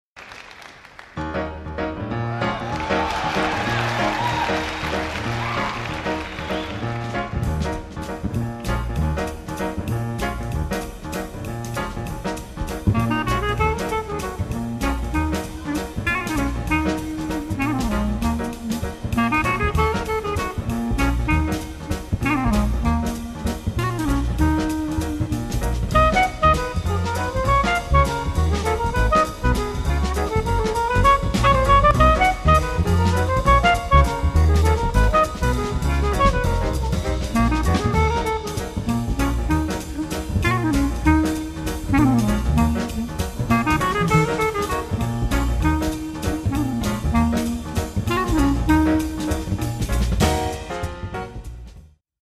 Theme tune
a classic jazz work